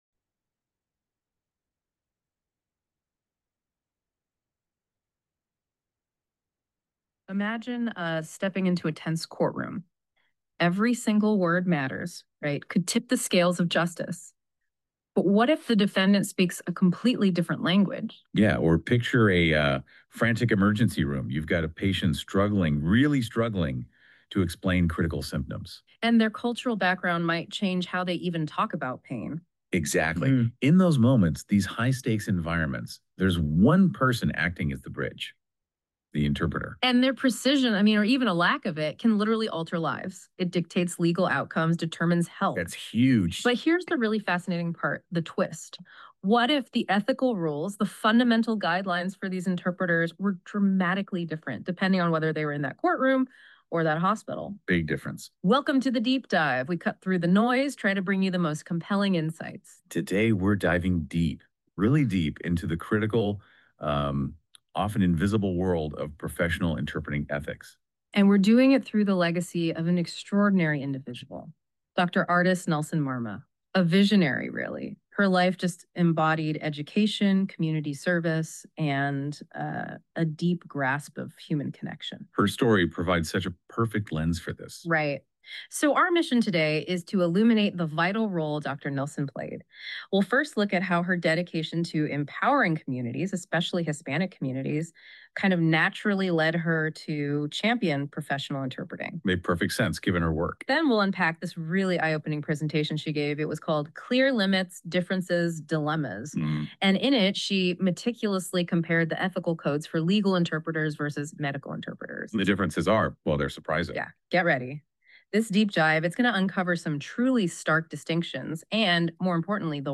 It was generated using NotebookLM.